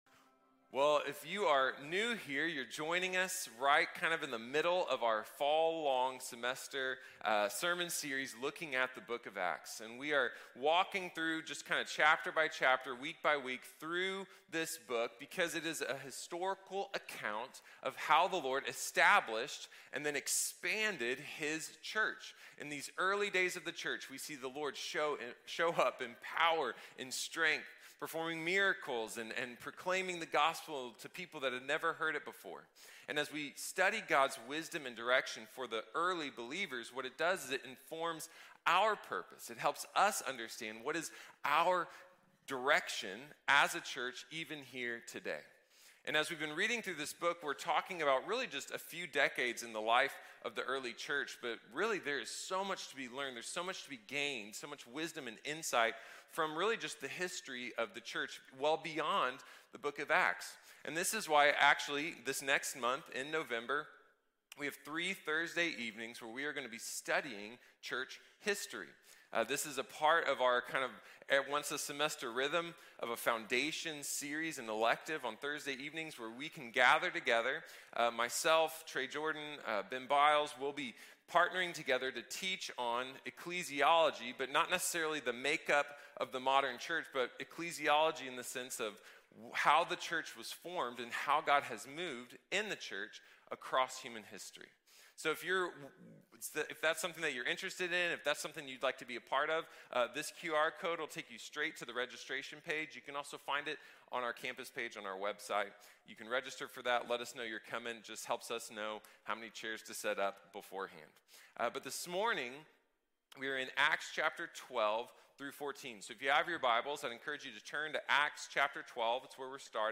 Made to Multiply | Sermon | Grace Bible Church